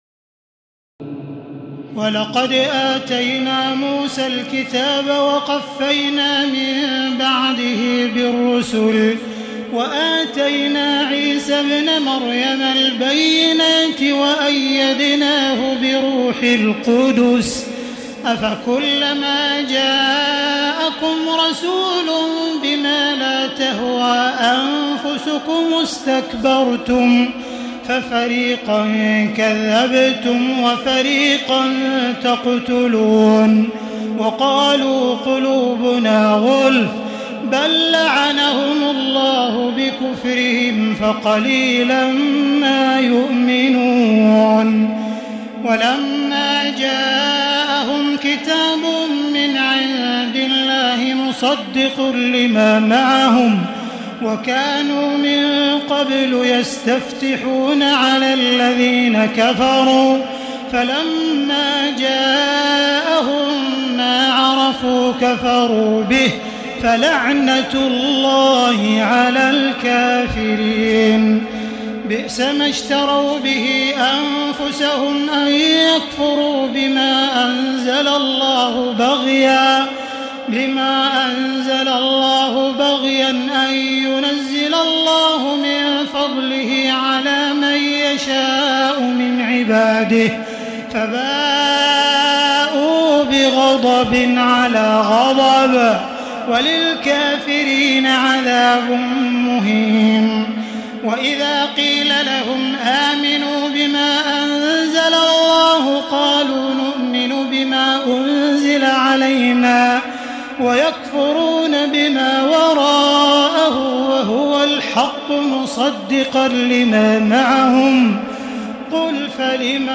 تراويح الليلة الأولى رمضان 1436هـ من سورة البقرة (87-157) Taraweeh 1st night Ramadan 1436 H from Surah Al-Baqara > تراويح الحرم المكي عام 1436 🕋 > التراويح - تلاوات الحرمين